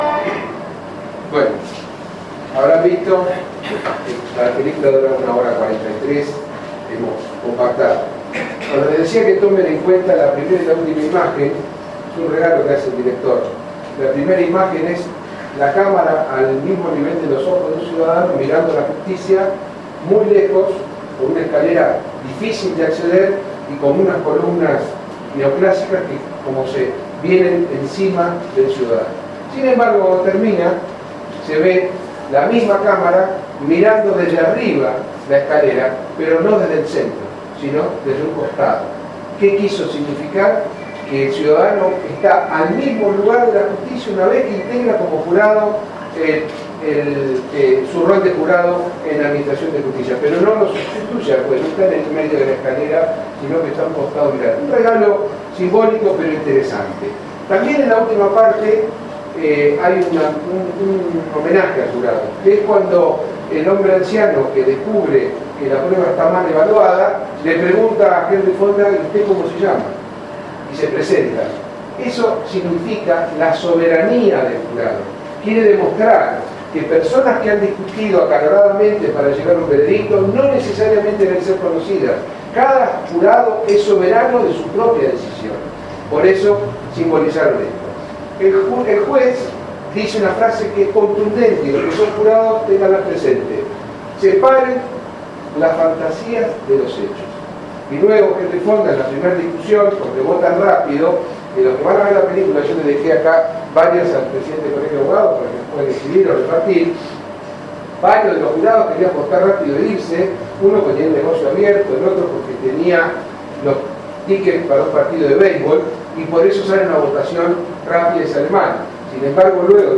El ministro de Justicia disertó sobre Juicio por Jurado en el CAA